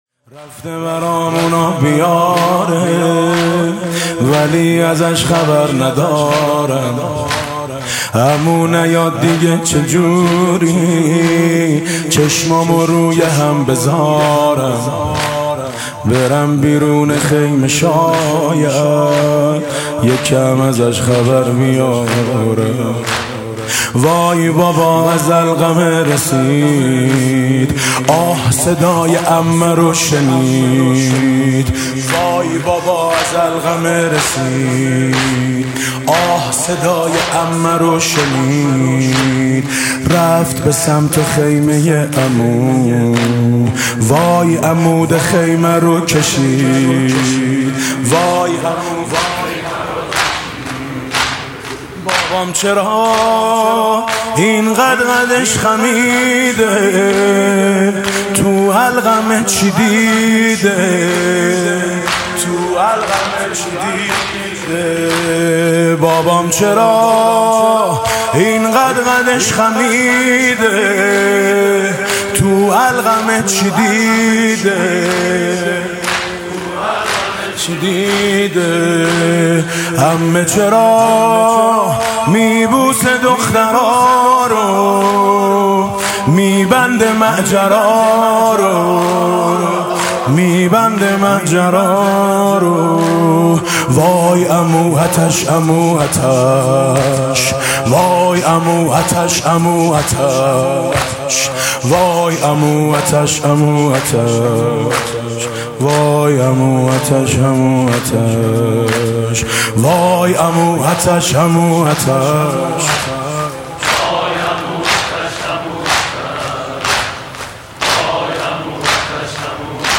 شب نهم محرم ۱۴۰۰
music-icon زمینه: چهار عموی نازنینم، پیکرشون روی زمینه حاج میثم مطیعی